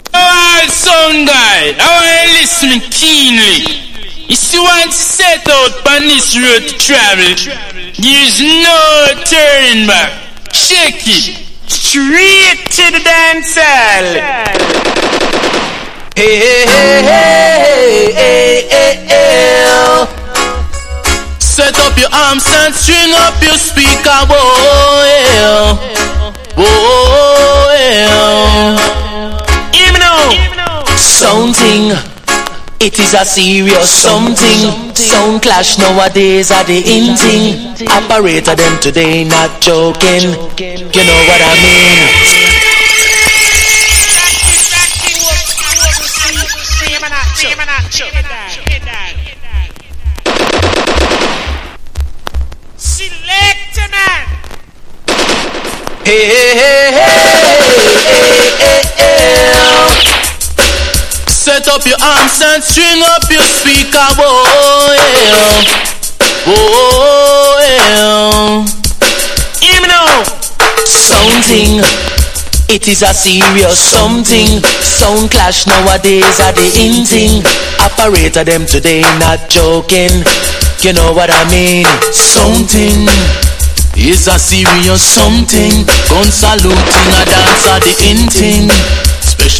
• REGGAE-SKA
# DANCE HALL